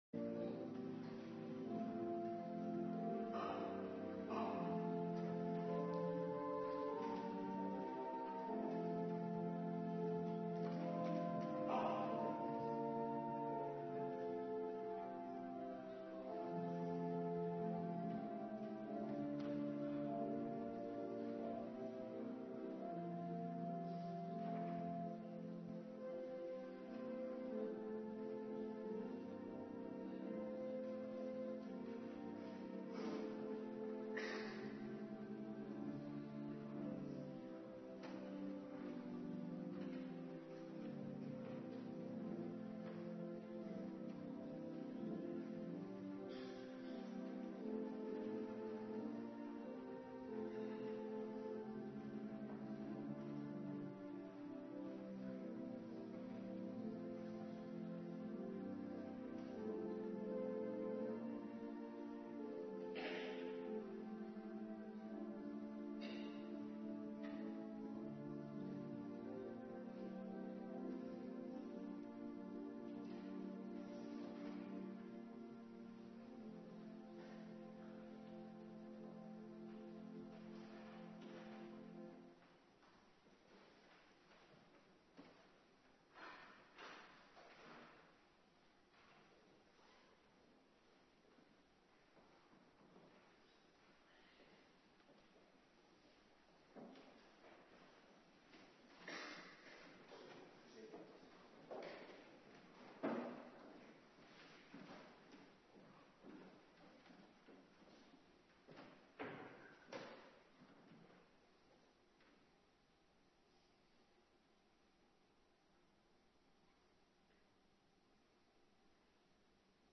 Woensdagavonddienst
19:30 t/m 21:00 Locatie: Hervormde Gemeente Waarder Agenda